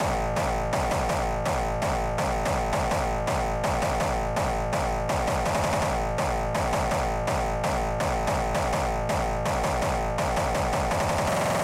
hardcore » Rock until gabber hit
描述：Gabber voc hit 확인하세요
标签： kick hardcore frenchcore techno stab hardstyle Gabber vocal gabber hard hit
声道立体声